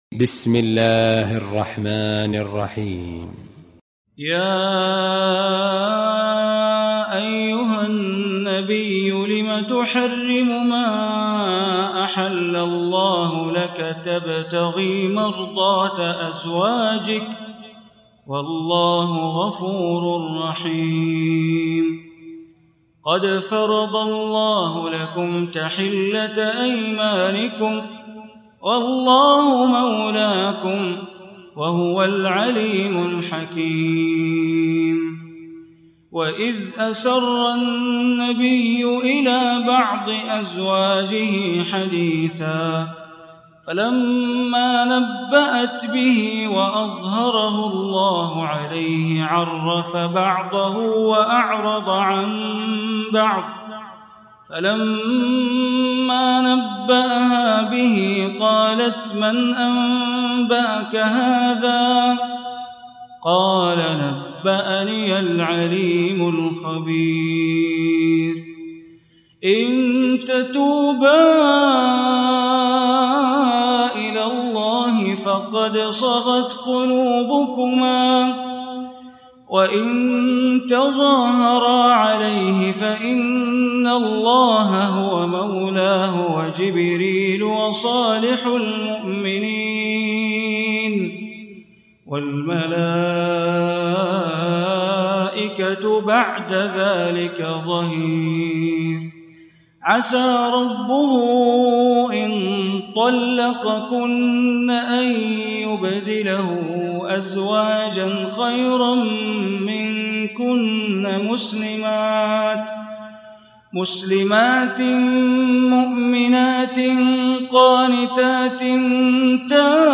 Surah Tahrim Recitation by Sheikh Bandar Baleela
Surah At-Tahrim, listen online mp3 tilawat / recitation in Arabic in the voice of imam e Kaaba Sheikh Bandar Baleela.